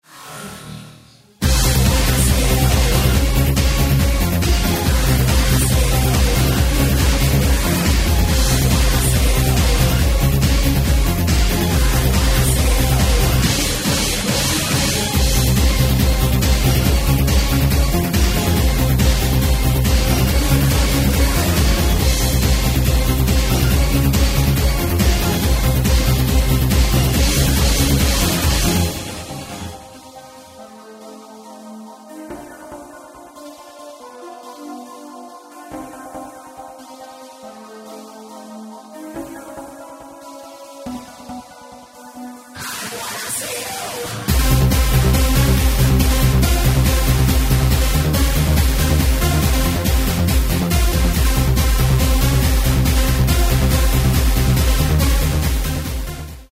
electro-industrial